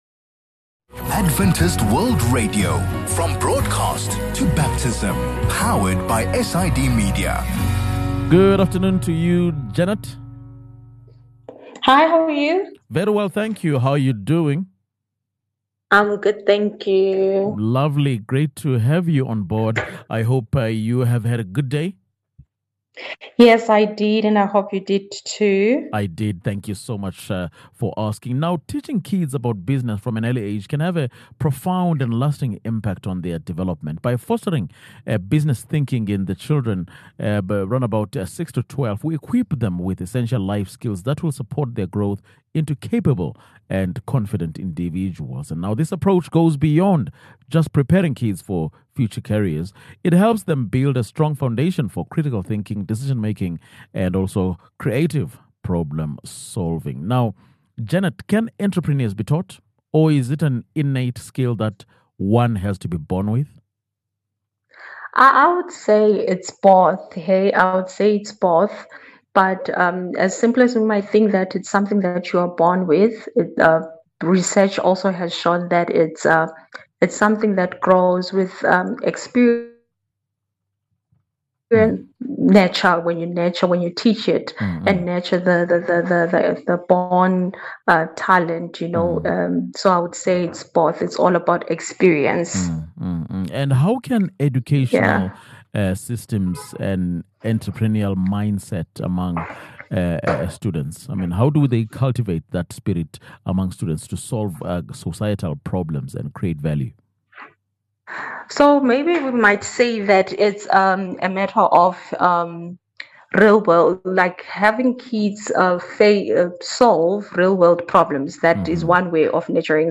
Teaching kids about business from an early age can have a profound and lasting impact on their development. In this conversation, we explore the benefits of early entrepreneurship education for students.